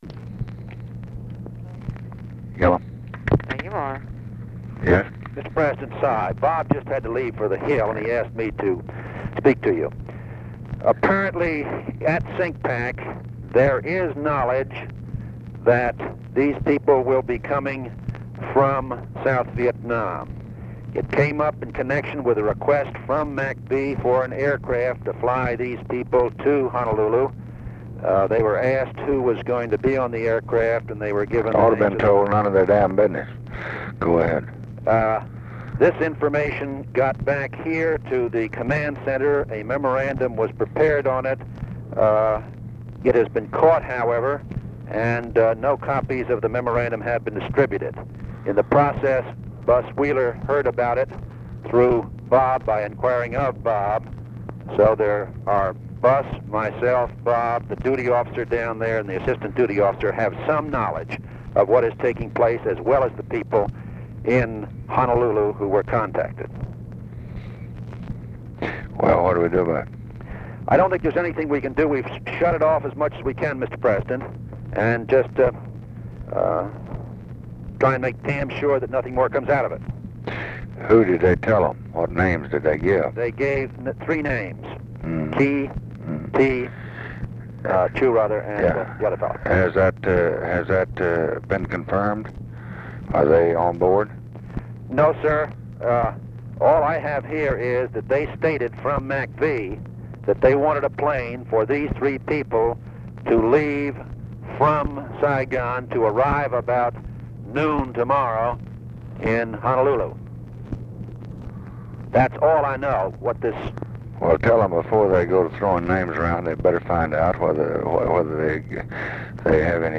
Conversation with CYRUS VANCE, February 4, 1966
Secret White House Tapes